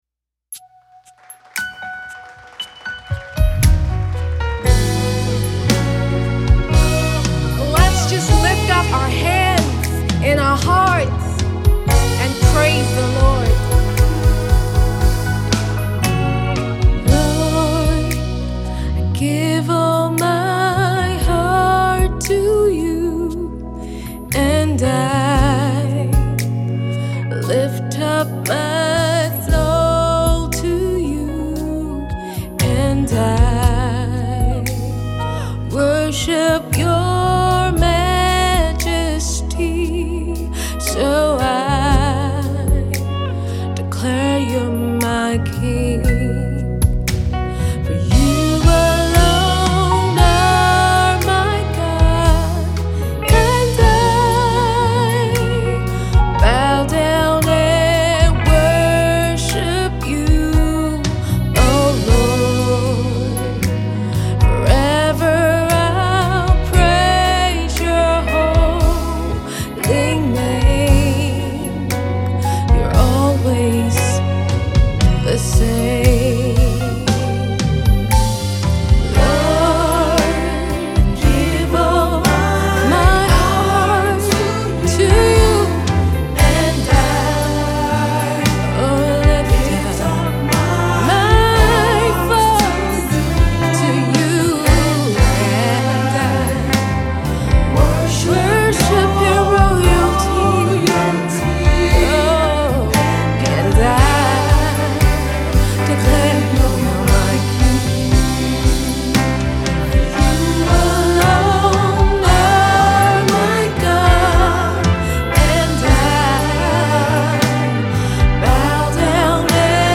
The worship song carries a tone of reckless abandonment